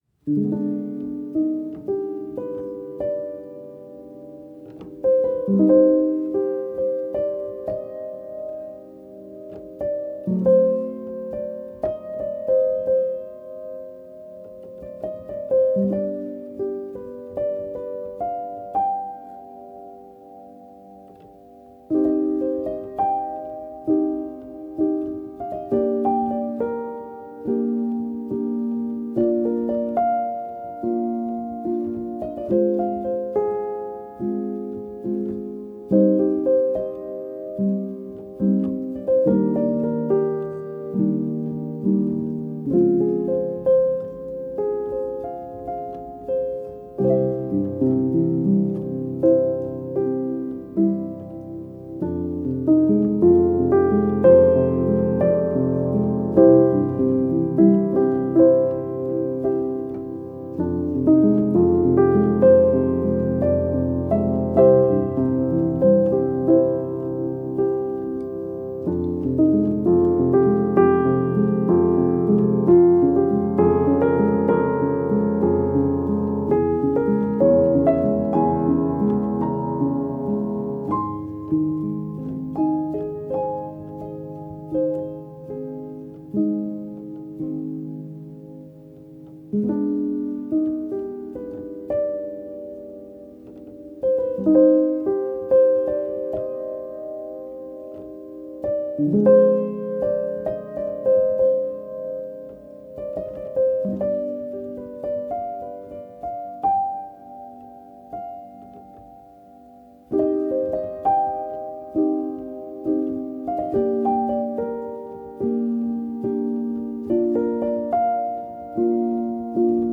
modern classical solo piano composition